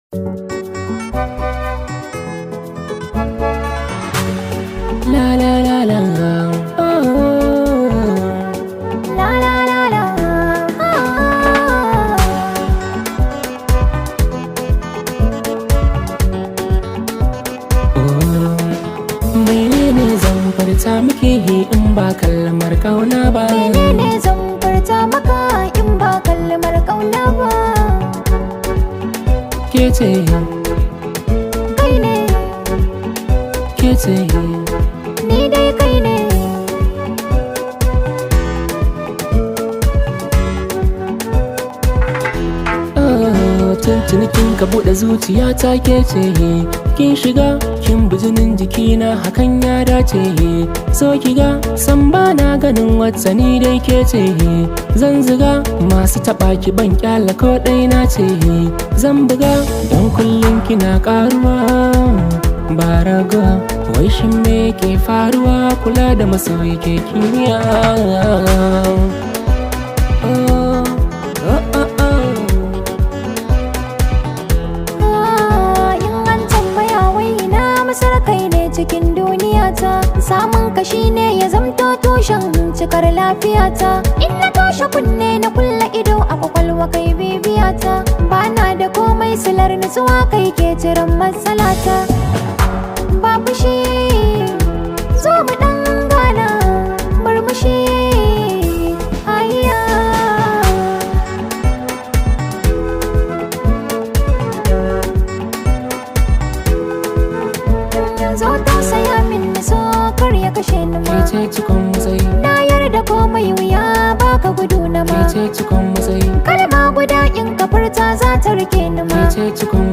Hausa Songs
This high vibe hausa song